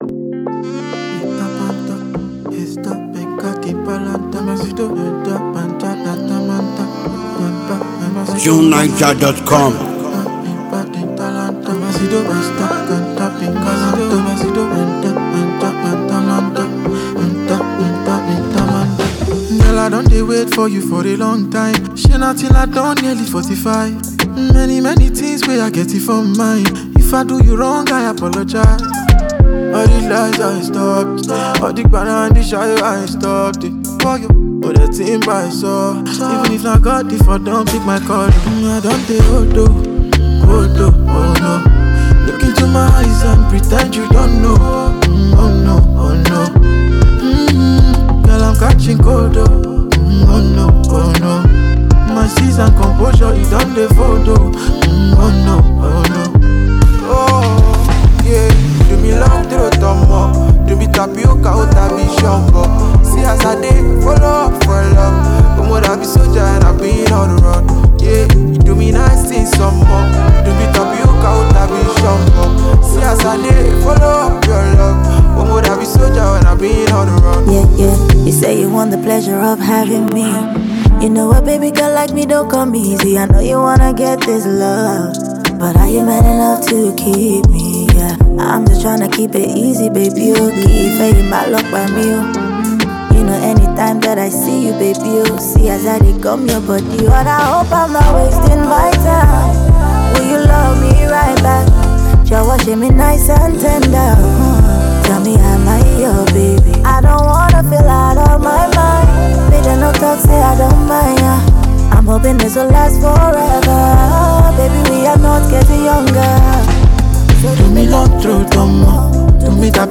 ” a sonic explosive and unique harmony that will uplift you.